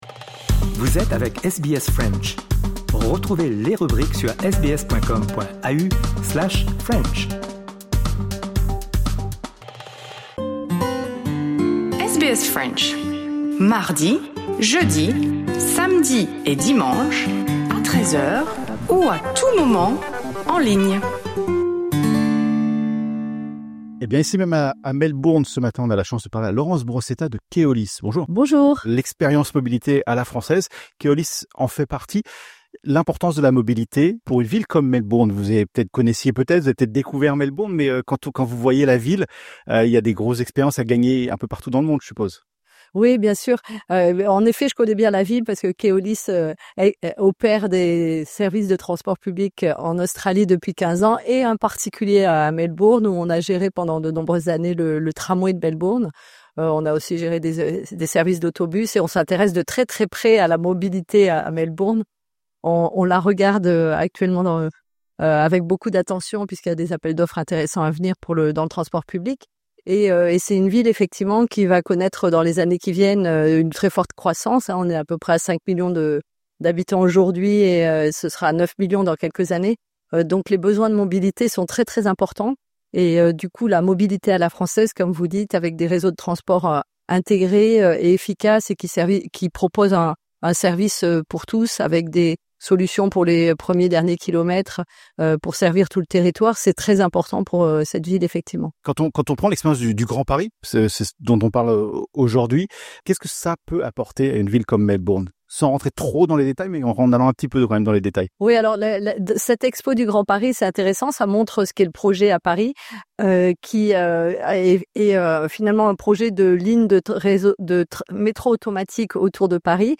Melbourne connaît une croissance démographique soutenue qui met fortement à l’épreuve son réseau de transport. Dans cet entretien